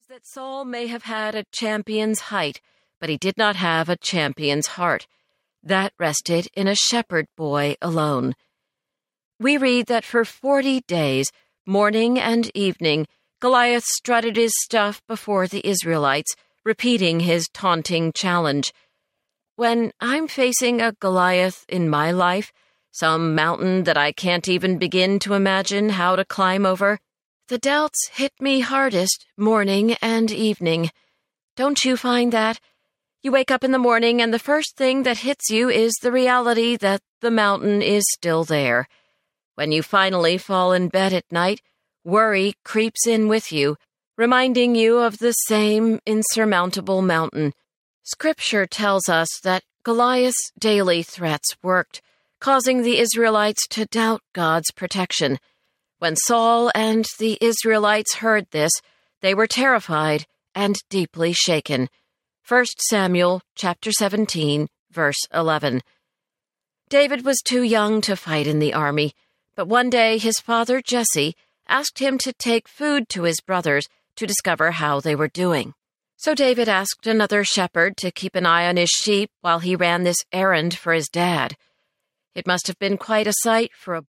The Longing in Me Audiobook
Narrator